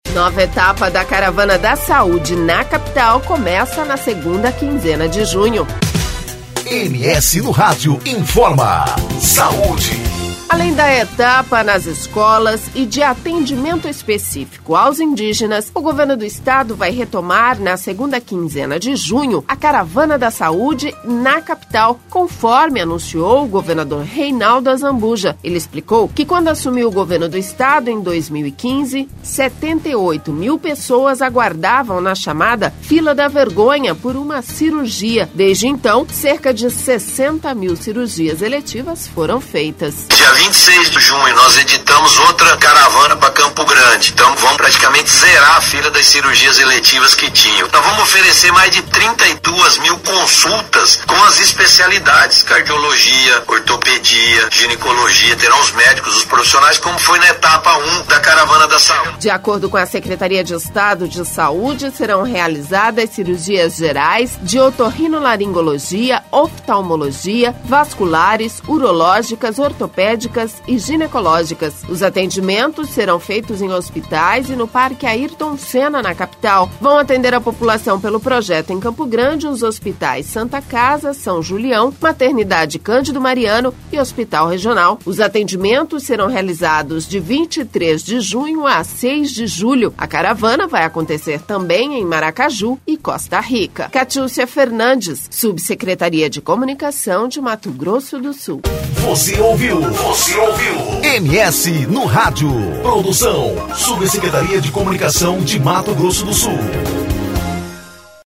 O anuncio foi feito pelo governador Reinaldo Azambuja durante entrevista ao programa Tribuna Livre, da rádio FM Capital.